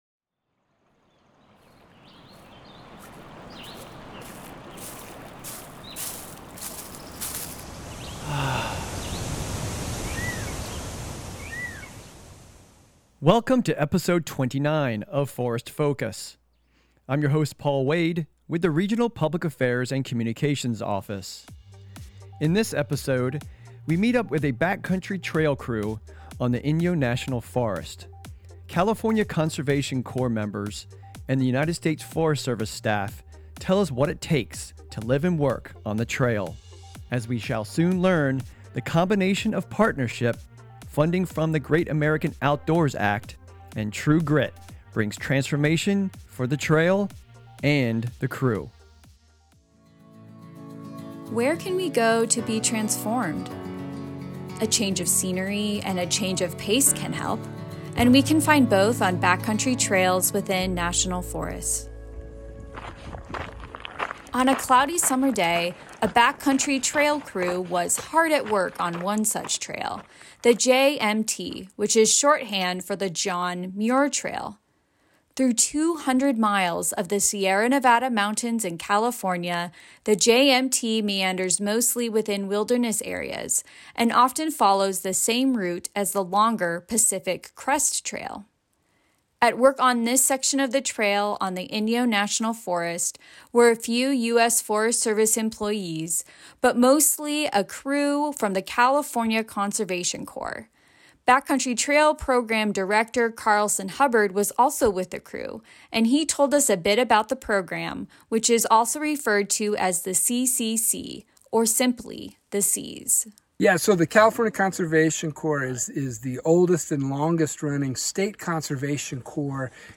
In this episode, we meet up with a backcountry trail crew on the Inyo National Forest. California Conservation Corps members and the United States Forest Service staff tell us what it takes to live and work on the trail. As we shall soon learn, the combination of partnership, funding from the Great American Outdoors Act, and True Grit brings transformation for the trail and the crew.